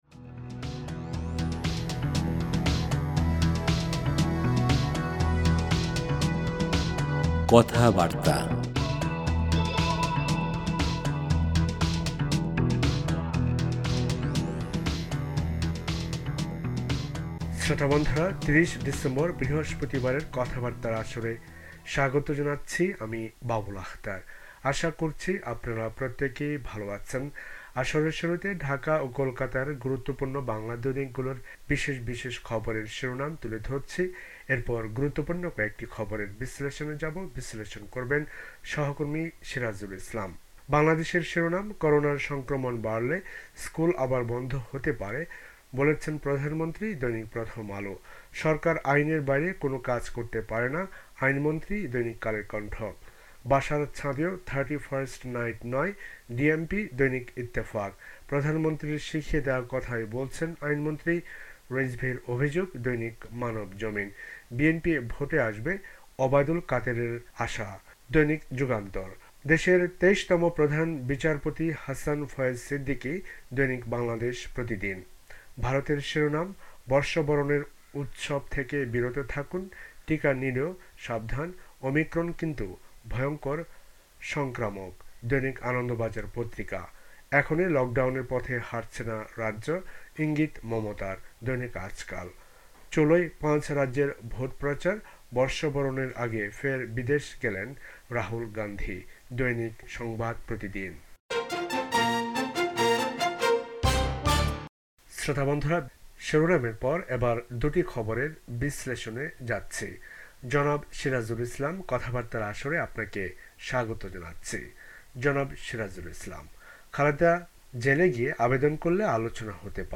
ঢাকা ও কোলকাতার পত্রপত্রিকার সব গুরুত্বপূর্ণ খবর